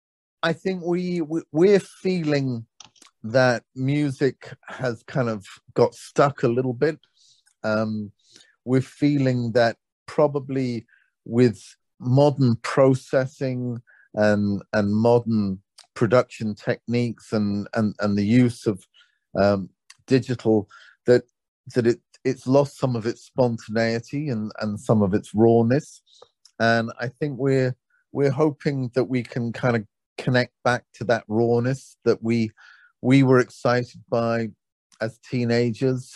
u2AdamClaytonOnBandDirection.mp3